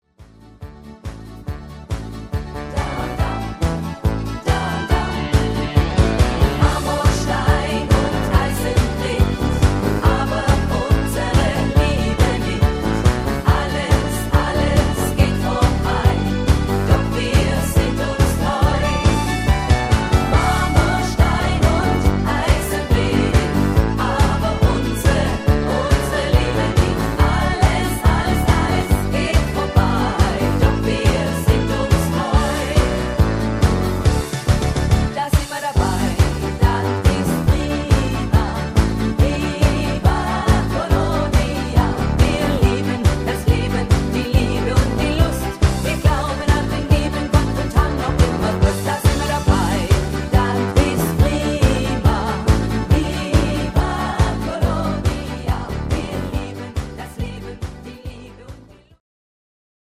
7806  Info   Schlager Medley